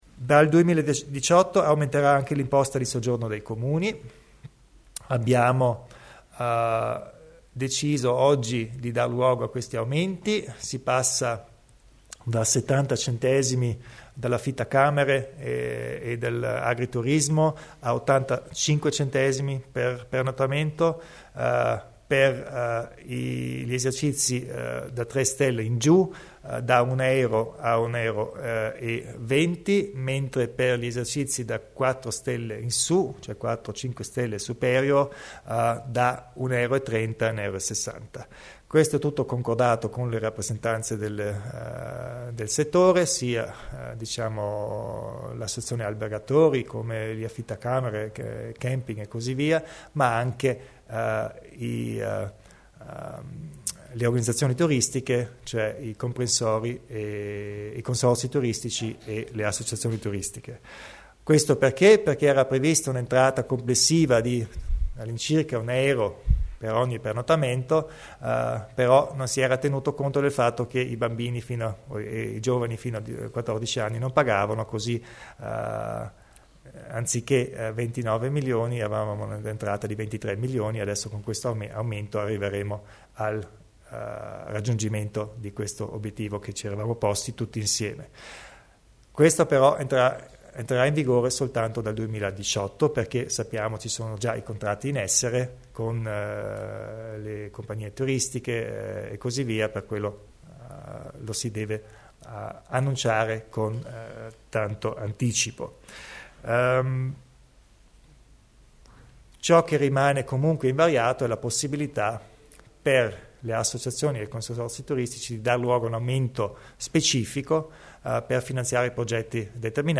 Il Presidente della Provincia Arno Kompatscher spiega le novità in tema di imposta di soggiorno